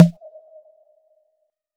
Closed Hats
Machine_Blip_Verb.wav